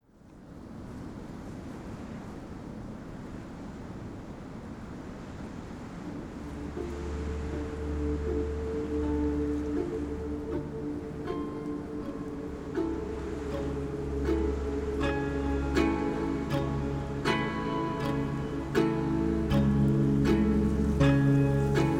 Атмосферная музыка русской зимы для театра